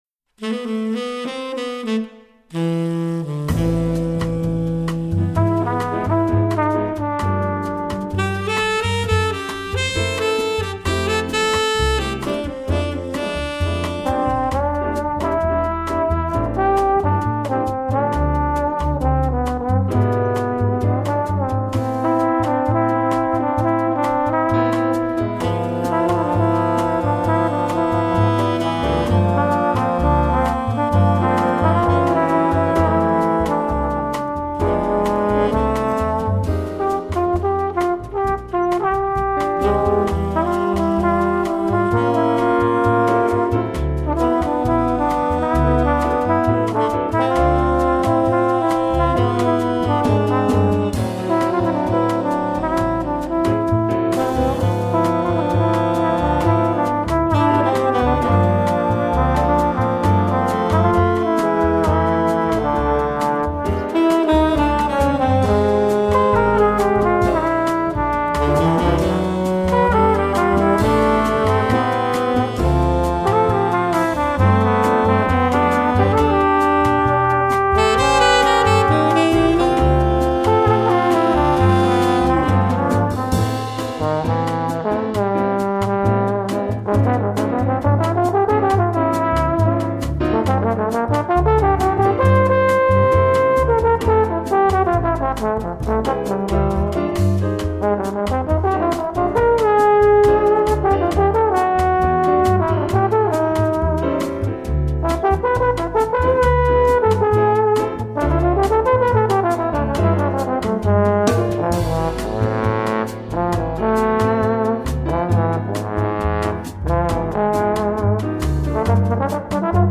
en concert
saxophone ténor et flûte
trompette
trombone
piano
contrebasse
batterie